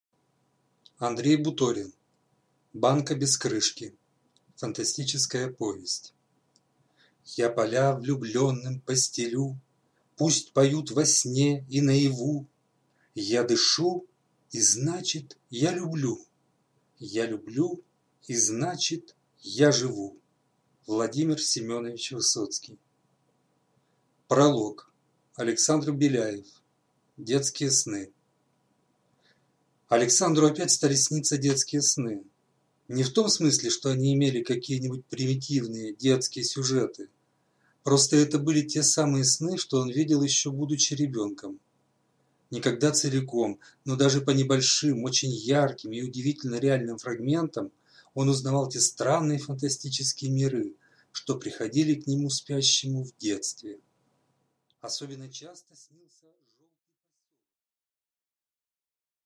Аудиокнига Банка без крышки | Библиотека аудиокниг